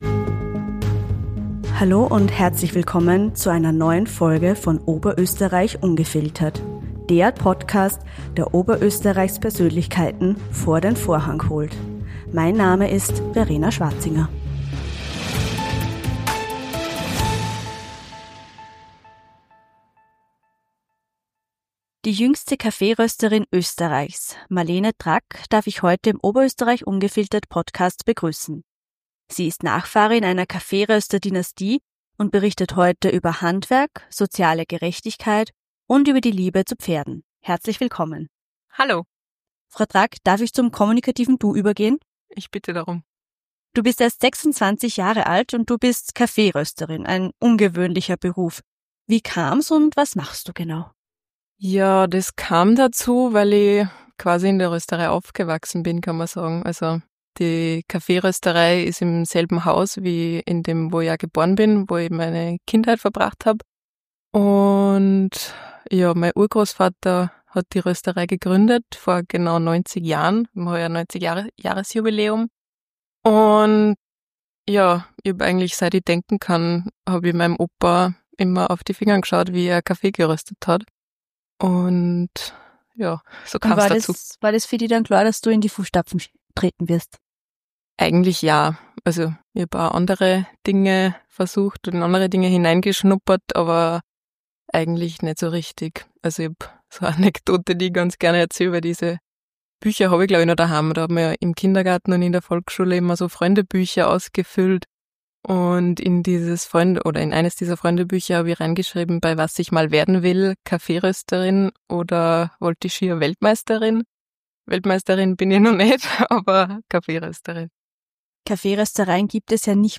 In einem offenen Gespräch offenbart sie, wie das Handwerk sie bereits seit ihrer Kindheit begleitet und warum Kaffee weit mehr als nur ein Getränk ist.